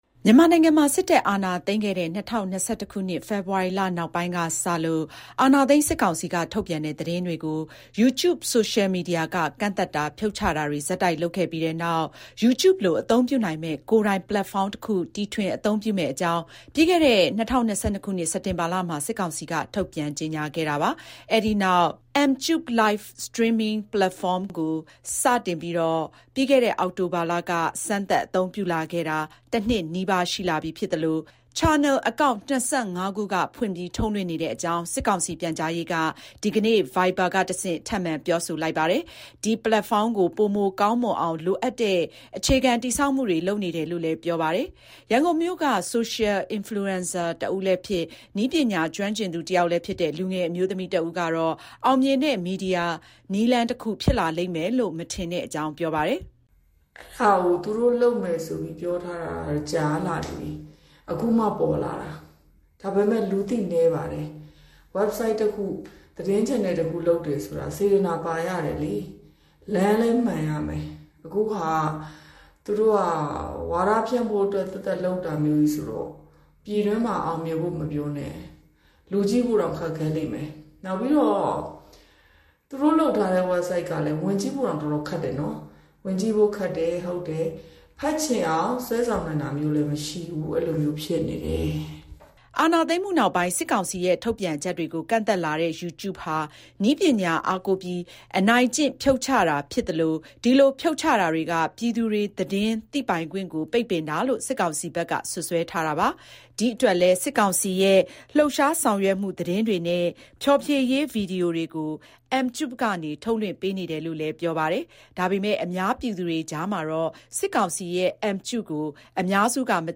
ရန်ကုန်မြို့က Social Influencer တဦးလည်းဖြစ်၊ နည်းပညာ ကျွမ်းကျင်သူတယောက်လည်းဖြစ်တဲ့ လူငယ် အမျိုးသမီးတဦးကတော့ အောင်မြင်တဲ့ မီဒီယာနည်းလမ်းတခု ဖြစ်လာလိမ့်မယ်လို့ မထင်တဲ့အကြောင်း ပြောပါတယ်။
အာဏာသိမ်းမှုနောက်ပိုင်း စစ်ကောင်စီရဲ့ ထုတ်ပြန်ချက်တွေကို ကန့်သတ်လာတဲ့ YouTube ဟာ နည်းပညာအားကိုးပြီး အနိုင်ကျင့် ဖြုတ်ချတာ ဖြစ်သလို ဒီလို ဖြုတ်ချတာတွေက ပြည်သူတွေ သတင်းသိပိုင်ခွင့်ကို ပိတ်ပင်တာလို့ စစ်ကောင်စီဘက်က စွပ်စွဲထားတာပါ။ ဒီအတွက်လည်း စစ်ကောင်စီရဲ့ လှုပ်ရှားဆောင်ရွက်မှု သတင်းတွေနဲ့ ဖျော်ဖြေရေး ဗီဒီယိုတွေကို Mtube ကနေ ထုတ်လွှင့်ပေးနေတယ်လို့လည်း ပြောဆိုပါတယ်။ ဒါပေမဲ့ အများပြည်သူတွေကြားမှာတော့ စစ်ကောင်စီရဲ့ Mtube ကို အများစုက မသိကြသလို၊ ယုံယုံကြည်ကြည် စောင့်ကြည့် အသုံးပြုတာမျိုး မရှိဘူးလို့ လူမှုကွန်ရက် အသုံးပြုတဲ့ ရန်ကုန်မြို့က လူငယ်တဦးက ပြောပါတယ်။ စစ်ကောင်စီက ဖန်တီးထားတဲ့ ပလက်ဖောင်းဖြစ်လို့ မကြည့်ချင်ကြဘူးလို့ သူက ပြောပါတယ်။